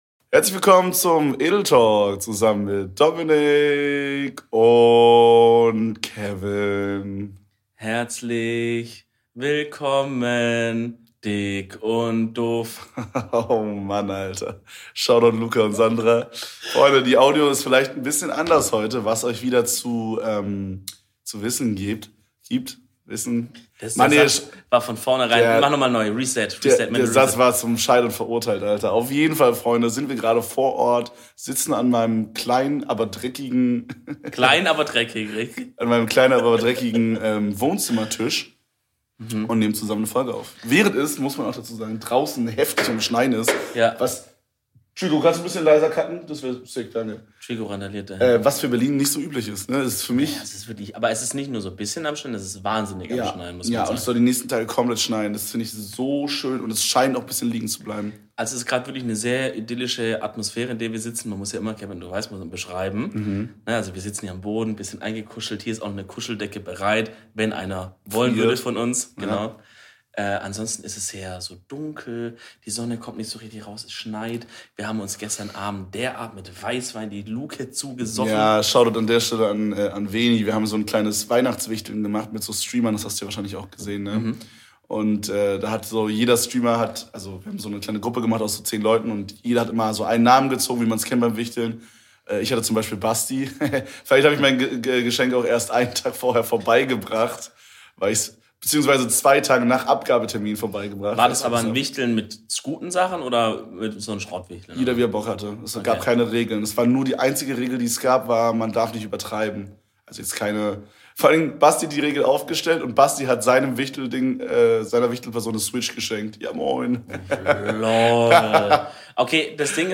Schnee in Berlin?! Passend zu einer der seltenen vor Ort Aufnahmen der beiden lässt sich die Wetterfee nicht lange bitten und schafft eine kuschelige Atmosphäre - die man hören kann!